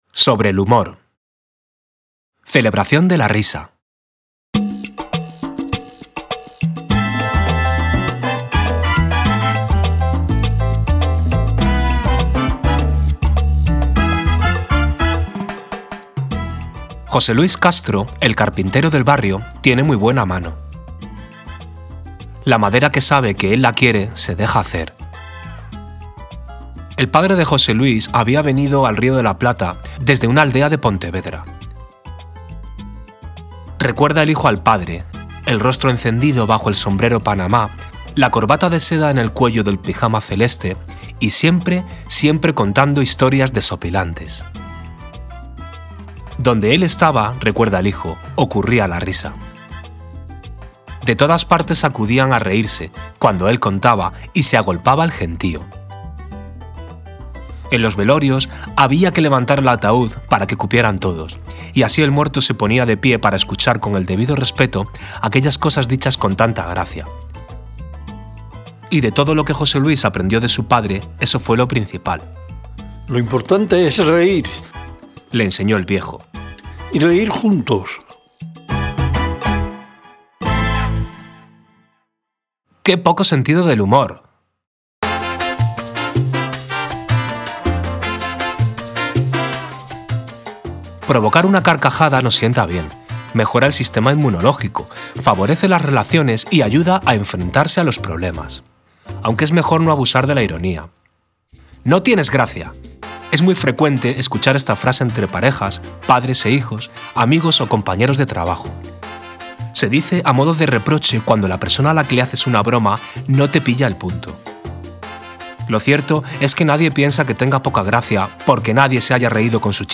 Lectura inicial. Sobre el humor